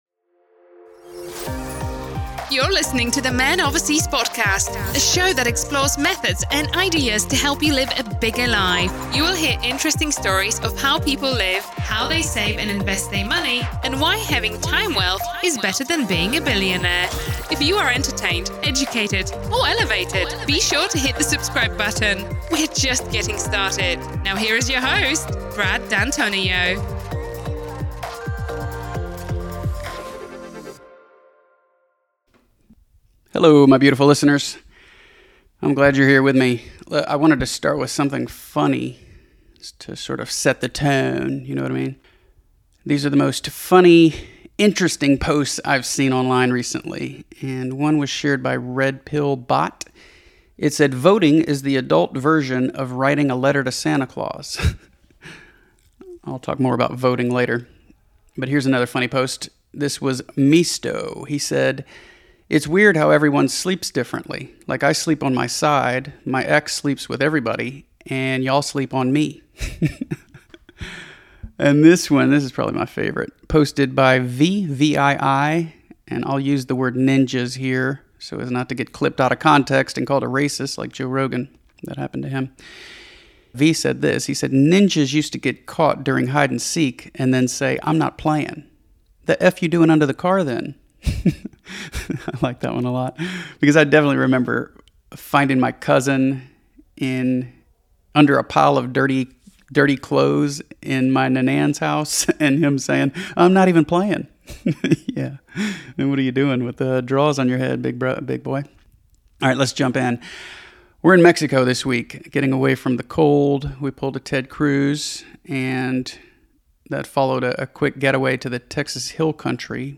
This solo episode opens with a few recent travel stories. Then I share highlights from my favorite Scott Adams book: How to Fail at Almost Everything and Still Win Big.